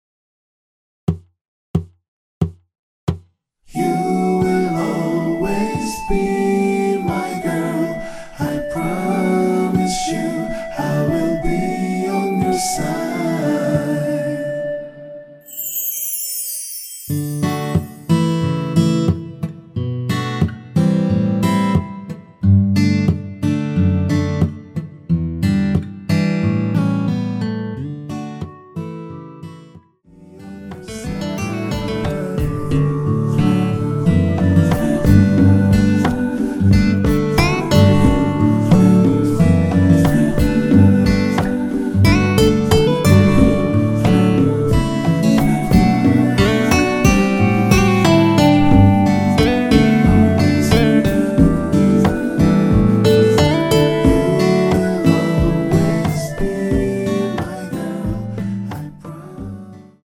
전주 없이 무반주로 노래가 시작 하는 곡이라서
노래 들어가기 쉽게 전주 1마디 넣었습니다.(미리듣기 확인)
원키에서(-2)내린 멜로디와 코러스 포함된 MR입니다.
앞부분30초, 뒷부분30초씩 편집해서 올려 드리고 있습니다.